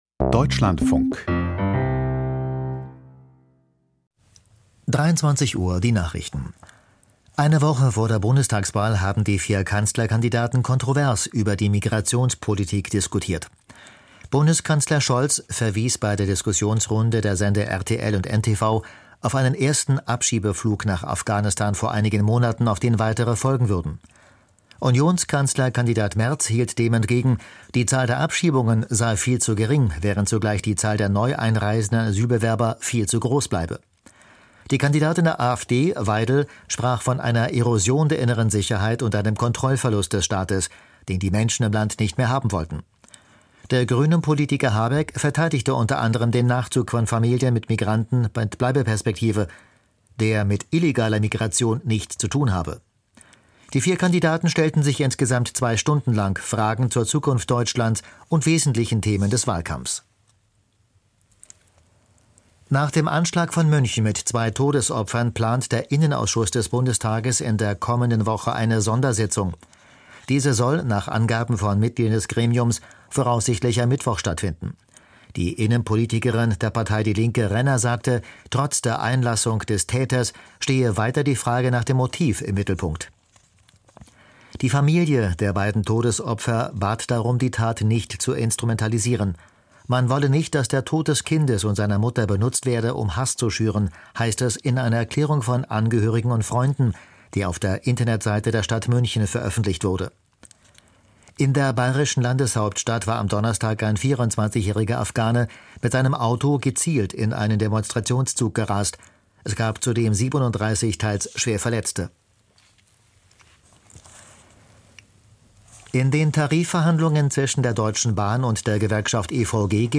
Die Deutschlandfunk-Nachrichten vom 16.02.2025, 23:00 Uhr